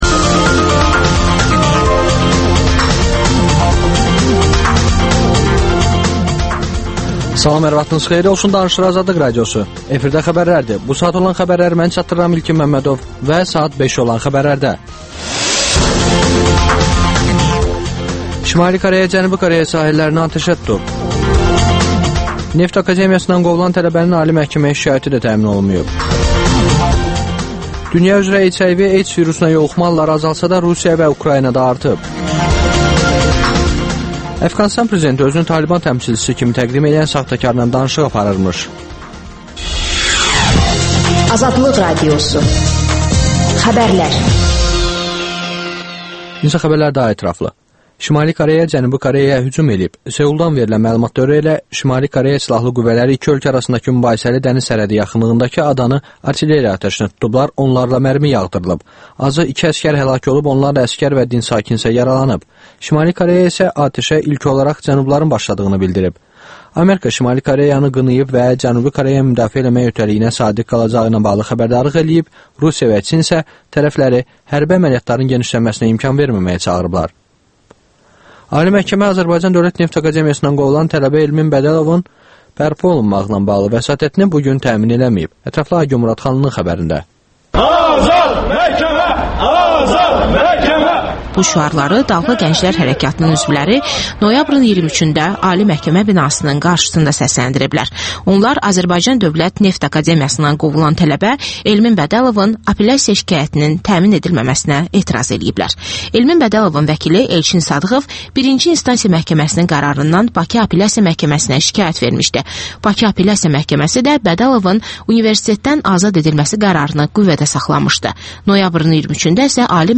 Bəyanata imza atmış Azərbaycan Xalq Cəbhəsi Partiyasının sədri Əli Kərimli və Klassik Xalq Cəbhəsi Partiyasının sədri Mirmahmud Mirəlioğlu canlı efirdə bəyanatla bağlı suallara cavab verirlər